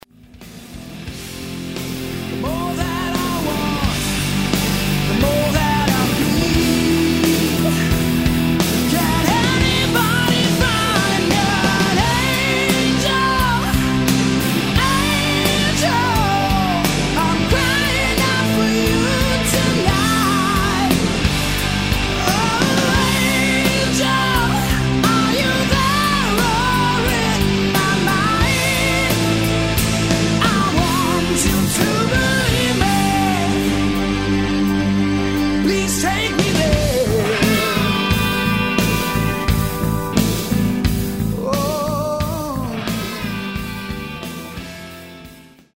Swiss Hard Rock band
For pure hard driven rock and roll